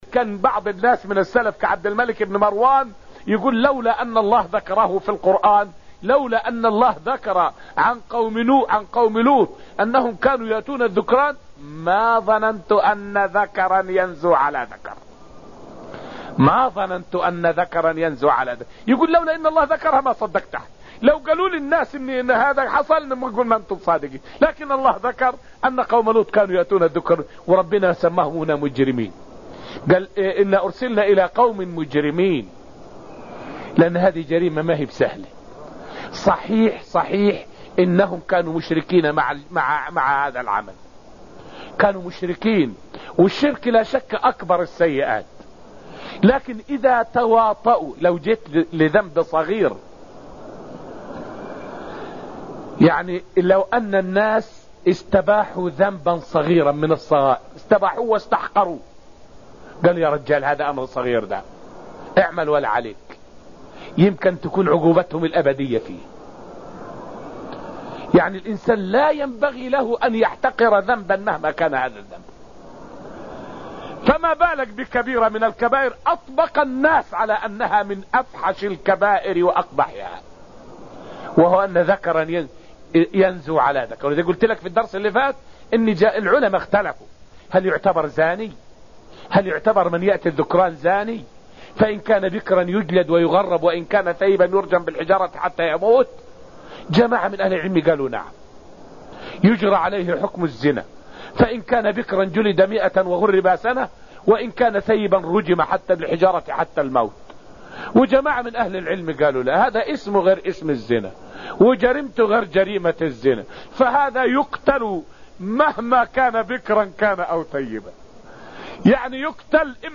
الذاريات تفسير تفسير القرآن دروس المسجد النبوي
فائدة من الدرس الرابع من دروس تفسير سورة الذاريات والتي ألقيت في المسجد النبوي الشريف حول استنكار الفطر السليمة لعمل قوم لوط واستبعاد وقوعها.